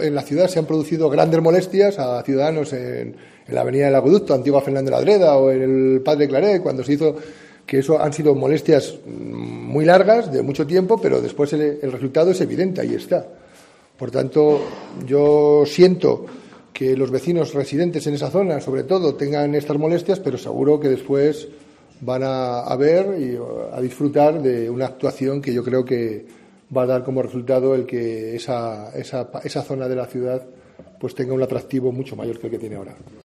José Mazarías, alcalde de Segovia, sobre las molestias que causarán las obras de Blanca de Silos